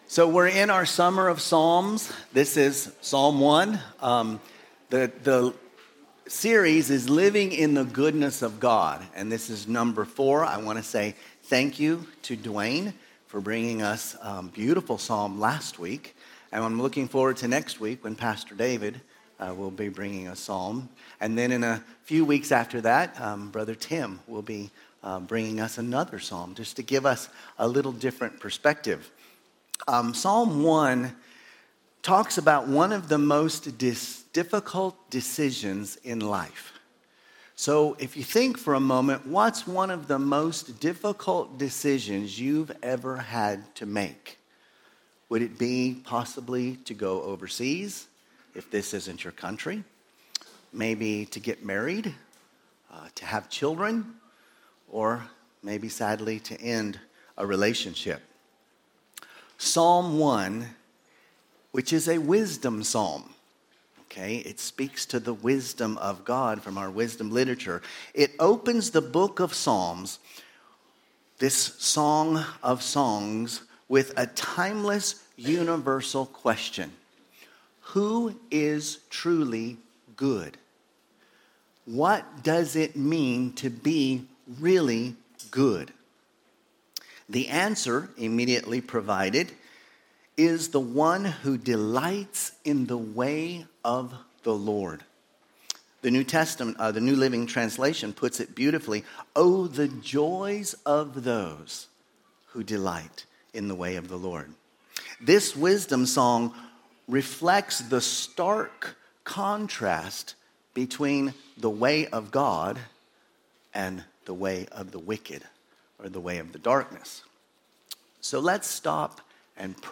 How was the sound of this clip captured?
From Series: "Sunday Service"